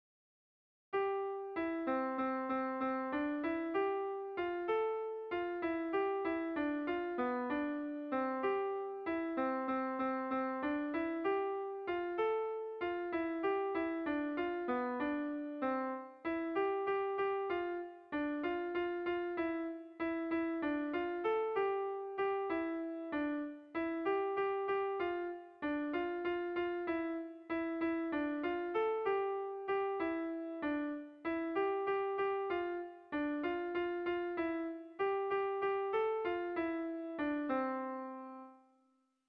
Irrizkoa
10eko handia izan arren, bi osagai bakarrik ditu doinuak.
Hamarreko handia (hg) / Bost puntuko handia (ip)
AABBB